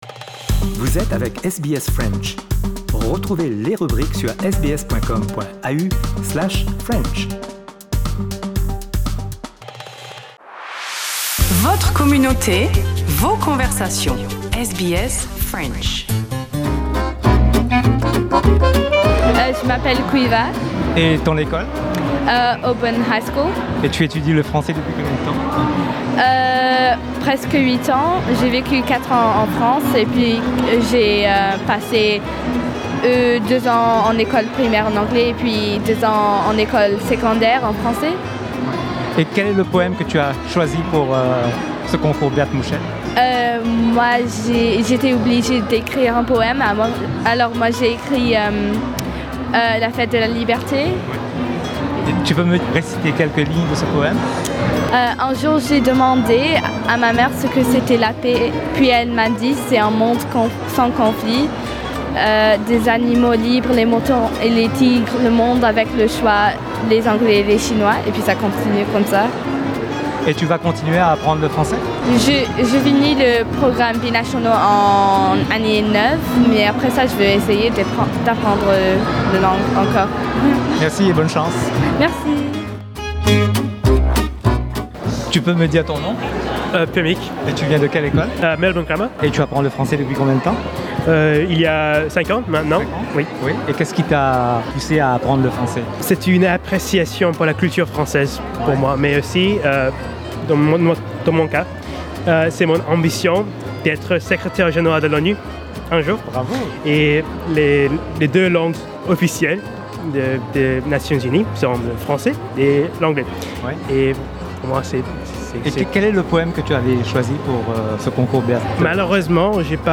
On vous propose une mosaïque de témoignages lors de la cérémonie de la remise des prix du Concours Berthe Mouchette 2019 de l’Alliance Française de Melbourne.